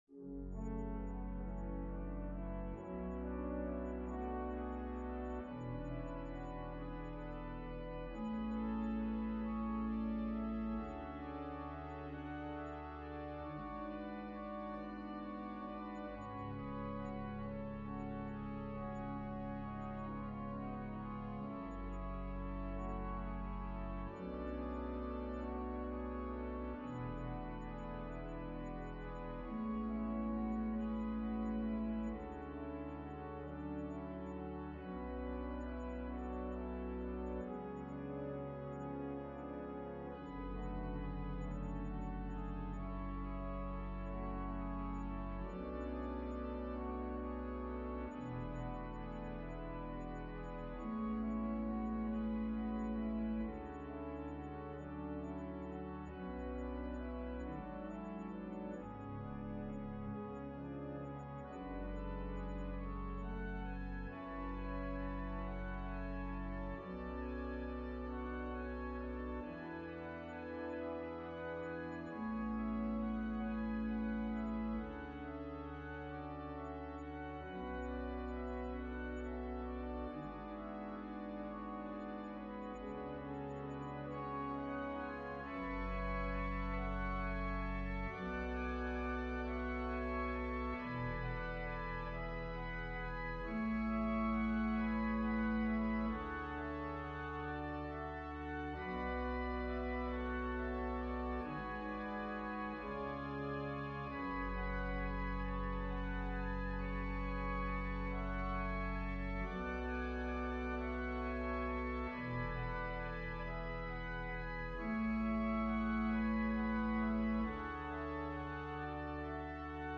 An organ solo arrangement